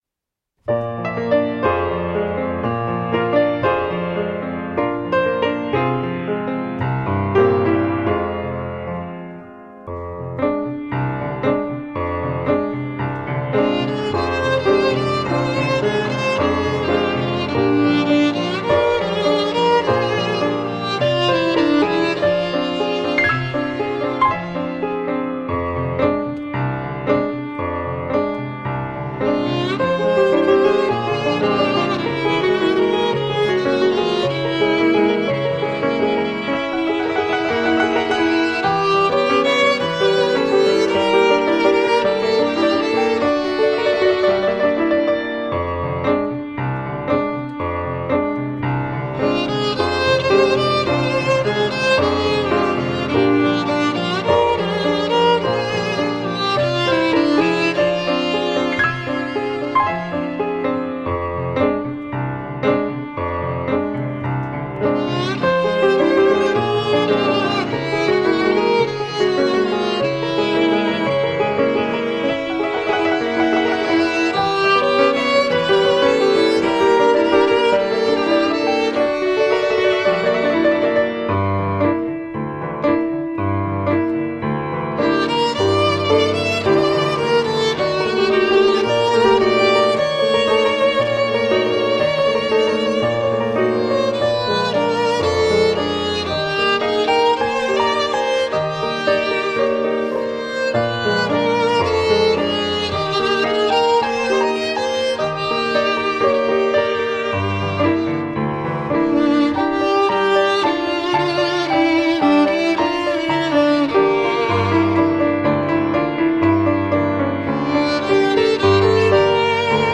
Exquisite original melodic pieces.